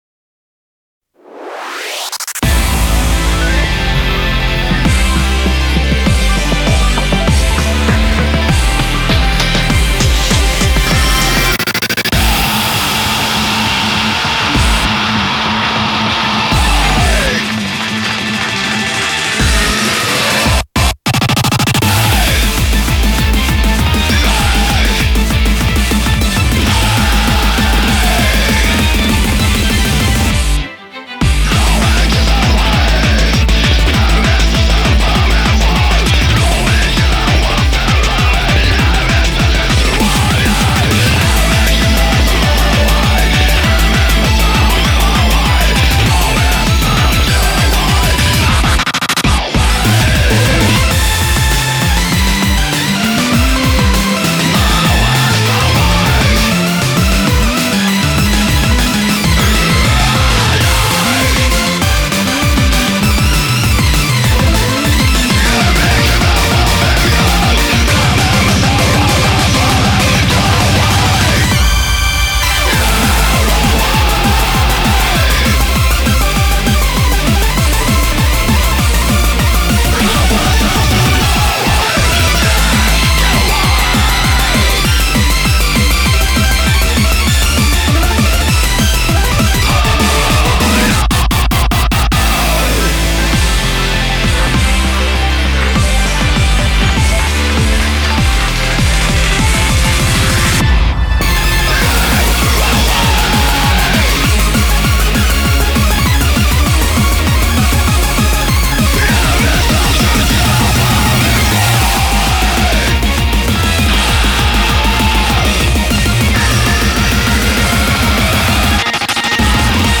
BPM99-396
Audio QualityLine Out